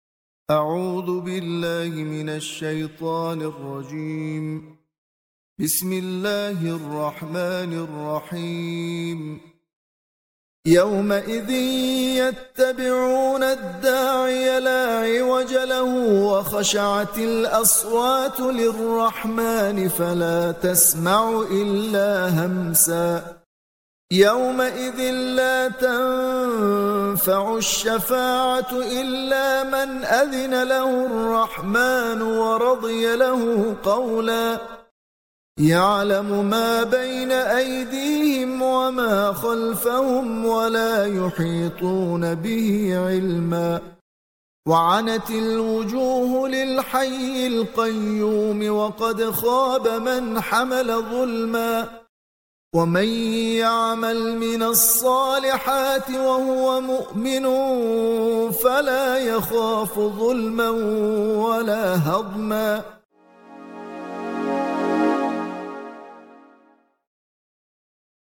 تلاوت آیاتی از قرآن برای کاهش گریه کودک